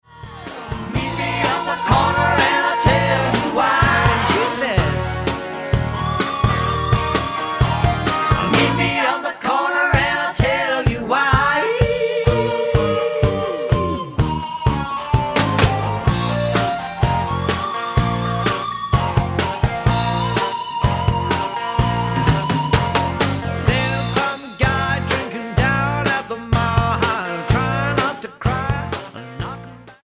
Genre / Stil: Country & Folk